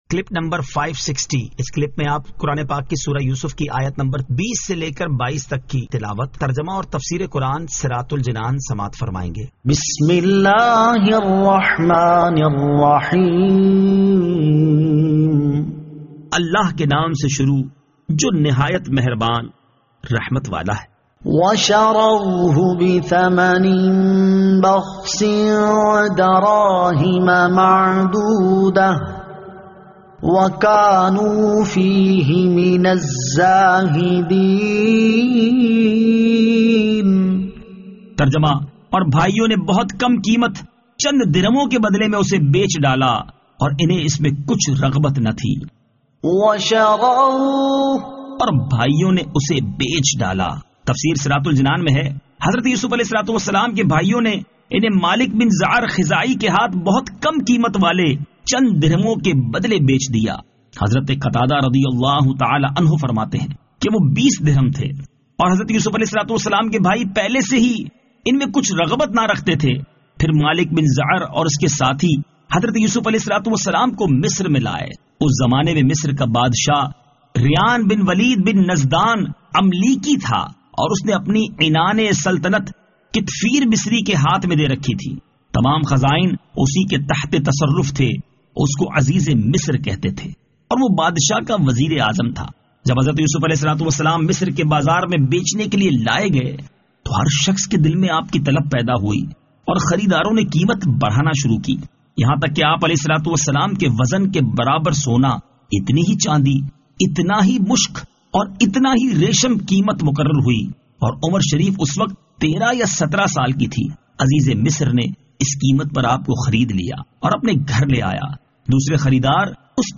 Surah Yusuf Ayat 20 To 22 Tilawat , Tarjama , Tafseer